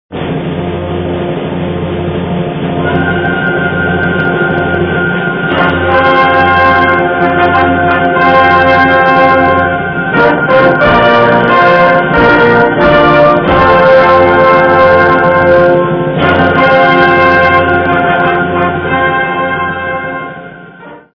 Gattung: Konzertstück
A Besetzung: Blasorchester Zu hören auf